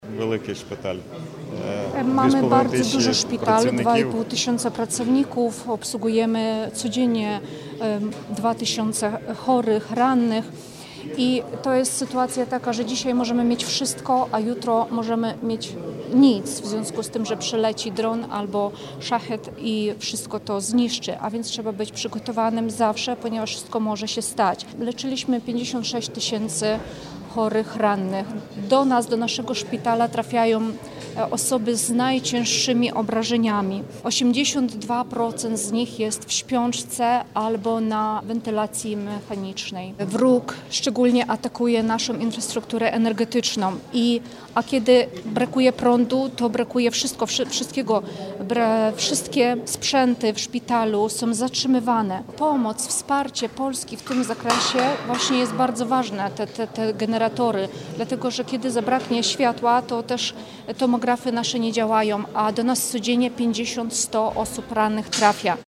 O tym rozmawiali eksperci podczas konferencji na Politechnice Wrocławskiej pod hasłem: „Bezpieczeństwo i funkcjonowanie podmiotów leczniczych w sytuacjach kryzysowych na podstawie doświadczeń Ukrainy. Zabezpieczenie medyczne wojsk na potrzeby obronne w Polsce”.